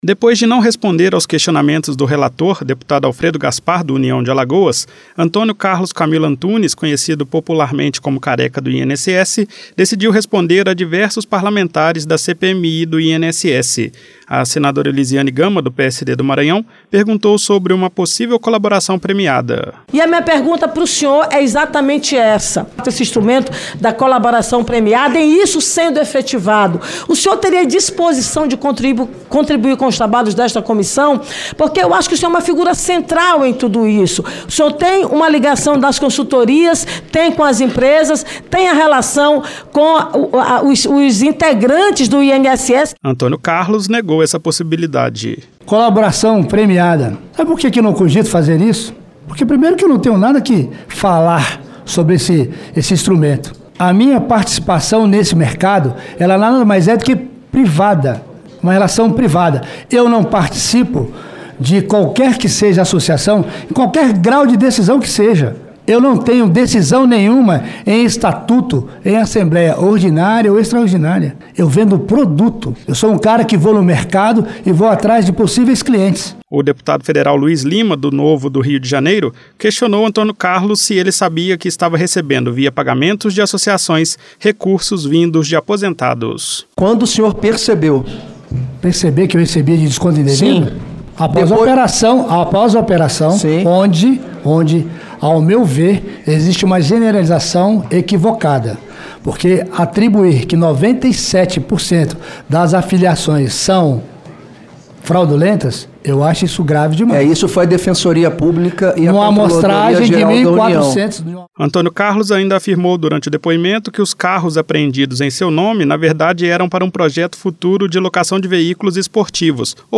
Em depoimento à CPMI